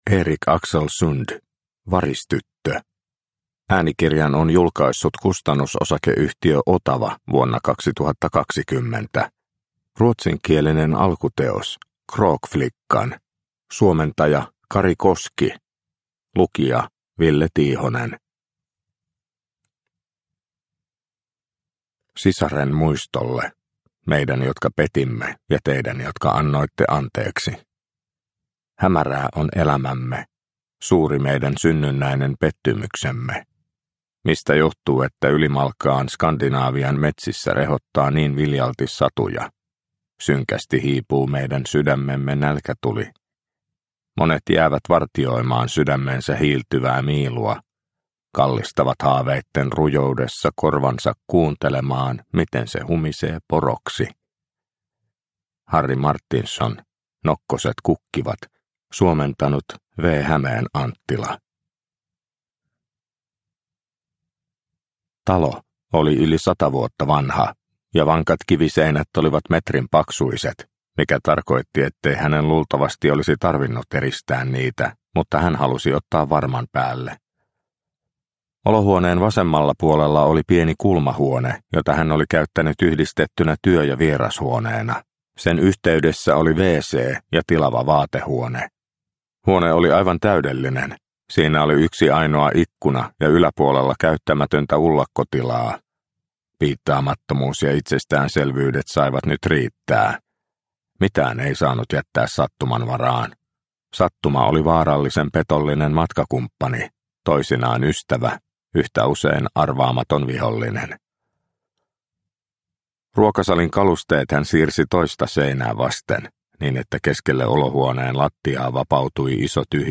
Varistyttö – Ljudbok – Laddas ner